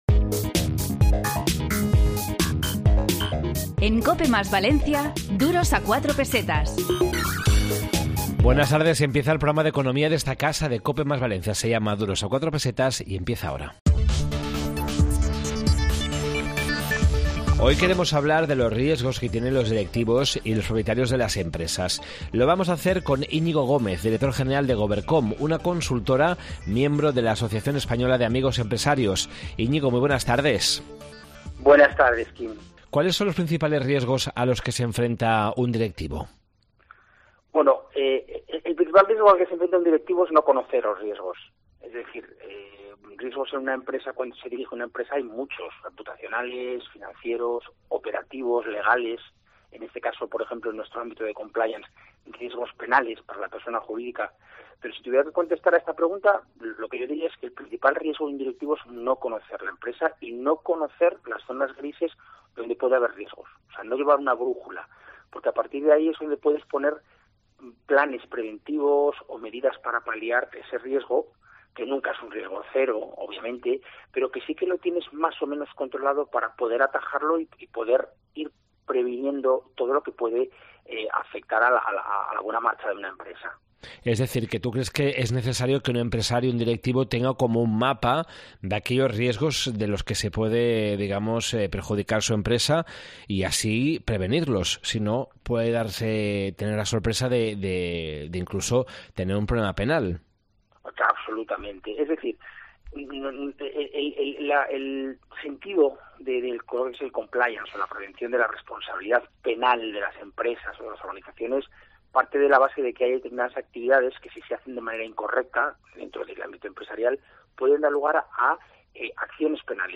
Esta semana en Duros a 4 Pesetas de COPE + Valencia, en el 92.0 de la FM, hemos preparado un programa dedicado al control de riesgos en las empresas, la indemnización por fallecimiento en caso de responsabilidad de terceros y la prevención de adicciones comportamentales en adolescentes.
entrevista